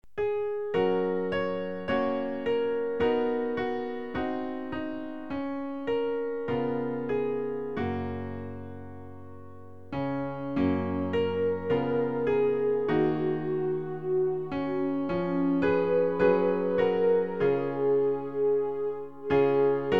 Klavier-Playback zur Begleitung der Gemeinde MP3 Download